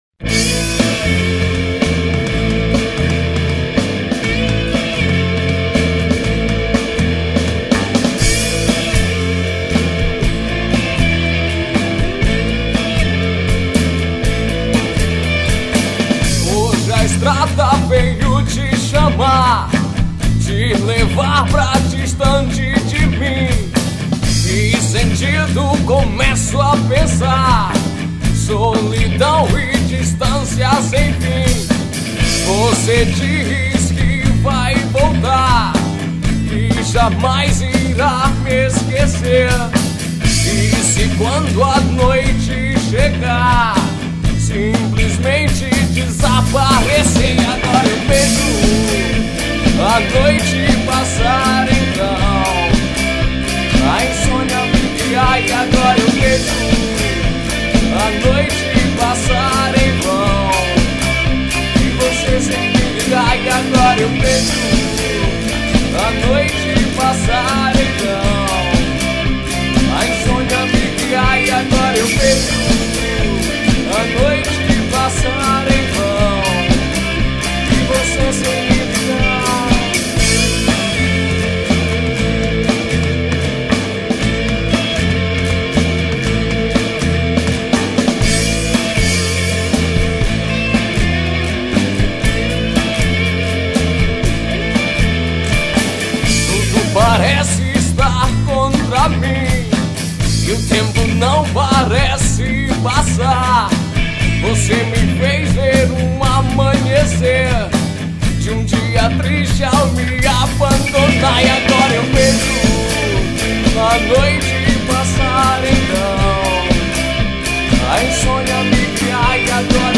2507   02:51:00   Faixa: 5    Rock Nacional